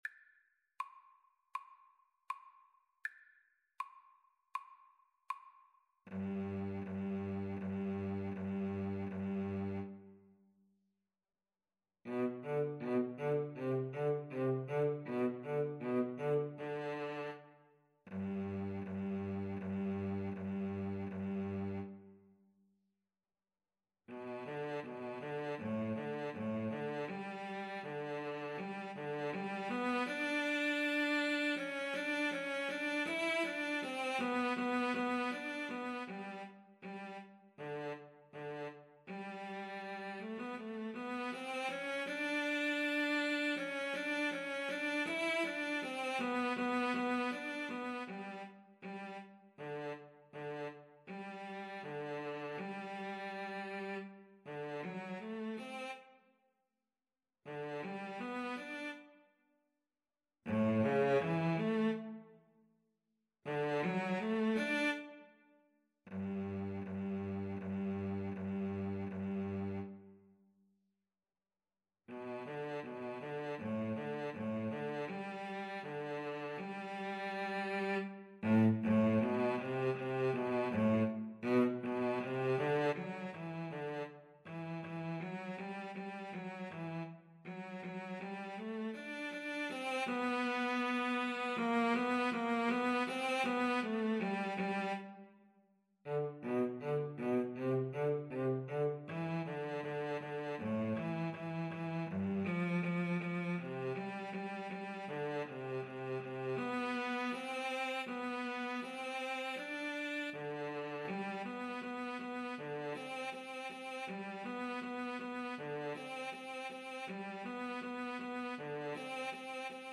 Free Sheet music for Cello Duet
G major (Sounding Pitch) (View more G major Music for Cello Duet )
Andante
4/4 (View more 4/4 Music)
Classical (View more Classical Cello Duet Music)